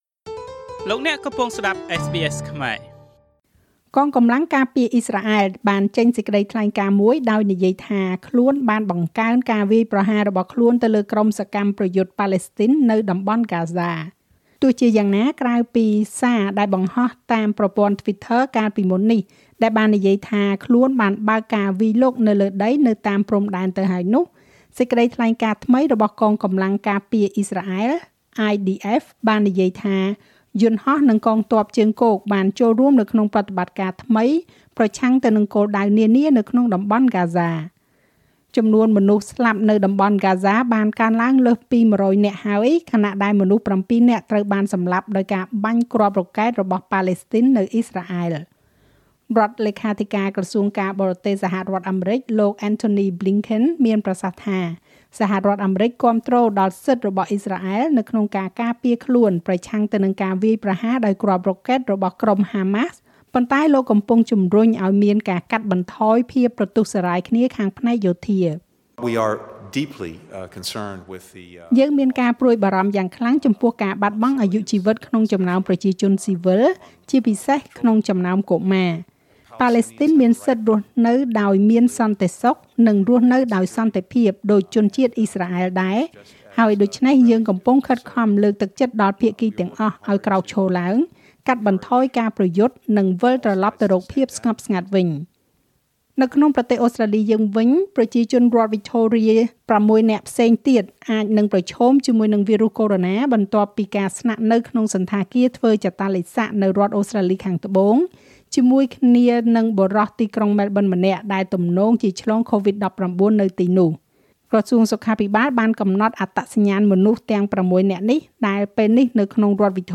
នាទីព័ត៌មានរបស់SBSខ្មែរ សម្រាប់ថ្ងៃសុក្រ ទី១៤ ខែឧសភា ឆ្នាំ២០២១